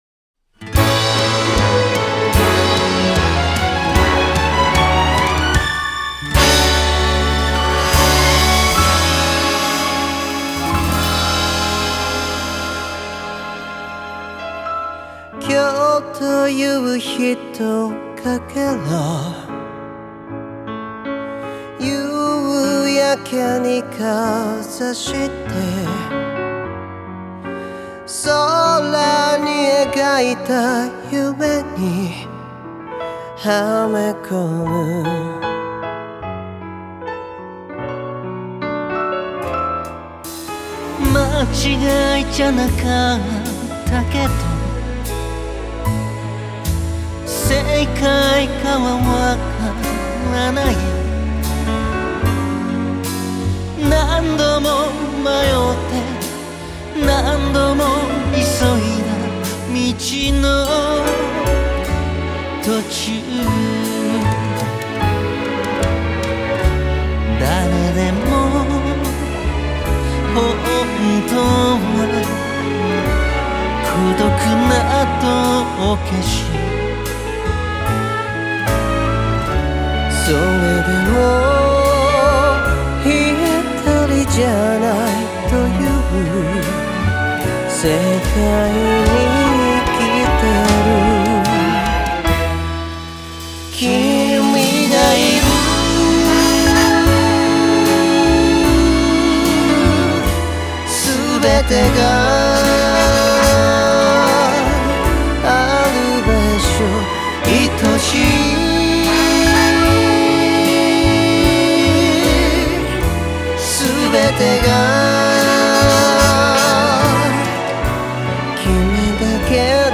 Piano&Strings（钢琴和弦乐谱）